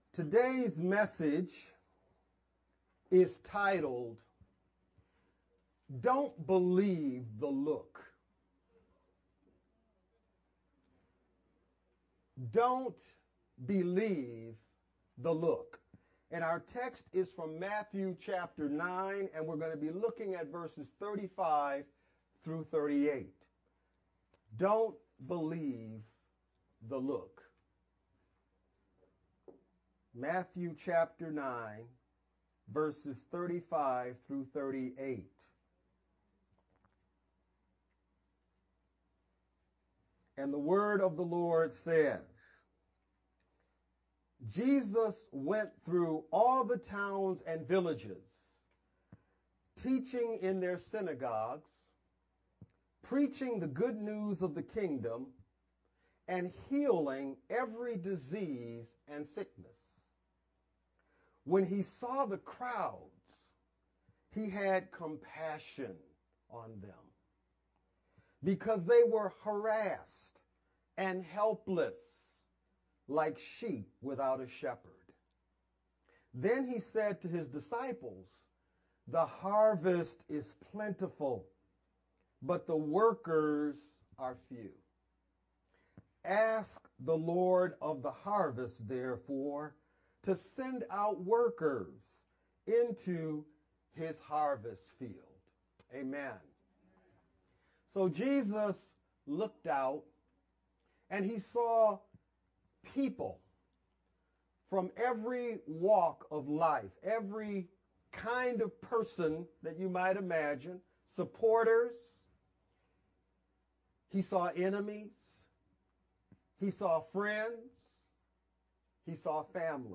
VBCC-Sermon-only-March-3rd_Converted-CD.mp3